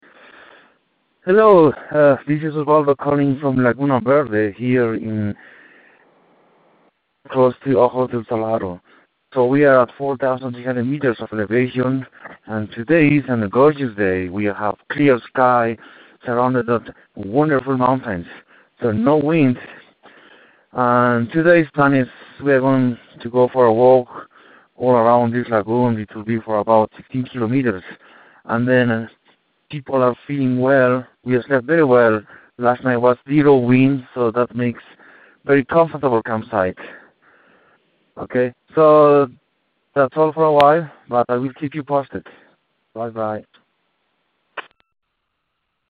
Ojos del Salado Expedition Dispatch